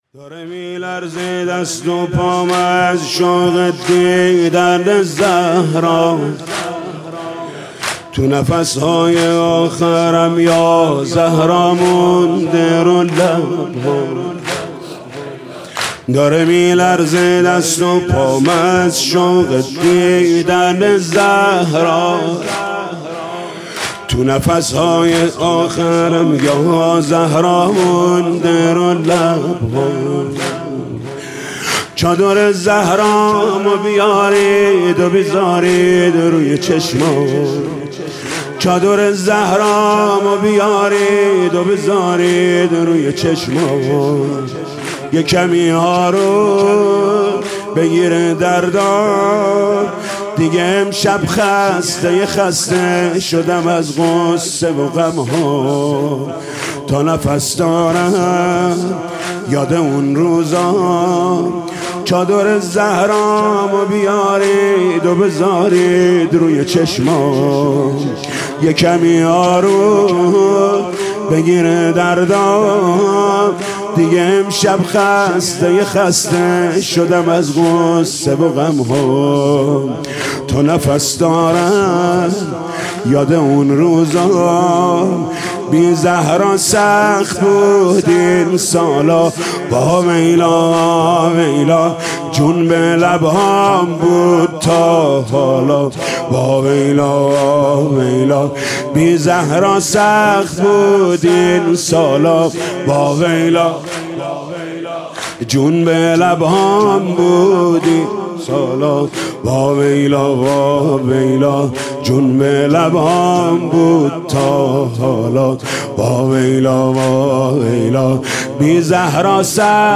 «شب بیستم» زمینه: داره میلرزه دست وپام